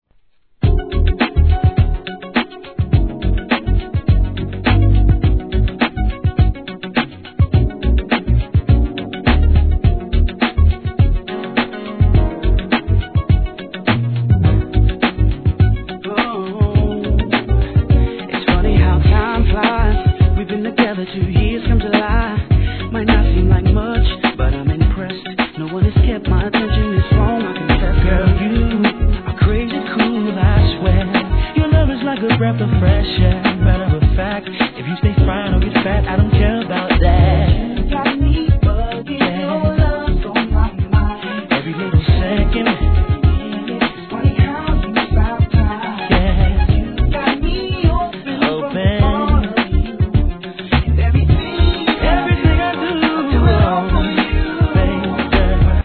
HIP HOP/R&B
美しいメロディーに伸びやかな清涼感あるコーラス・ワークで気持ちよく躍らせてくれます♪